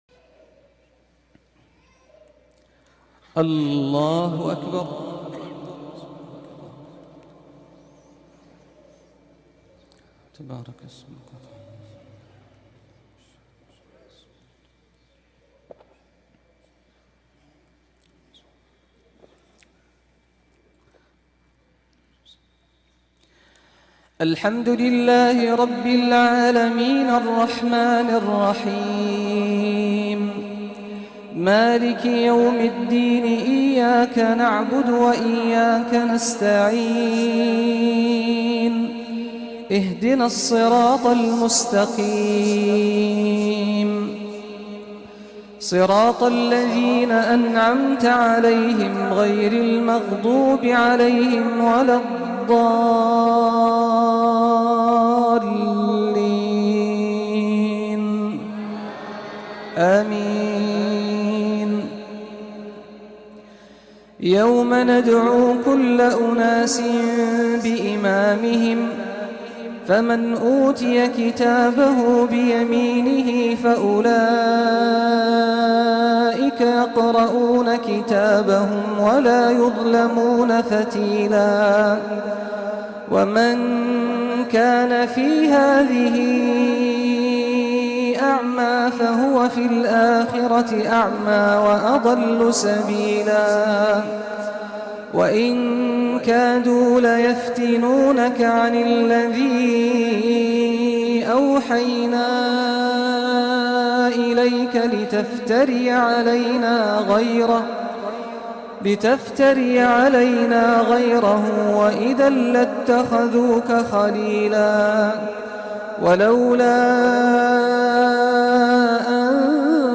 Surat Al Esraa Reciter Saad Al Ghamdi